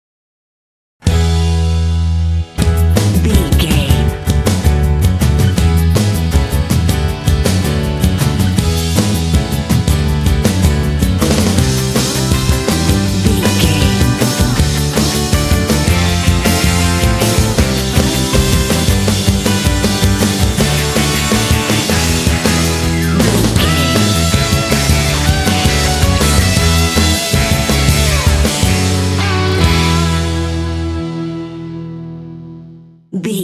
Uplifting
Ionian/Major
bouncy
happy
groovy
bright
acoustic guitar
bass guitar
drums
pop
rock
alternative rock
indie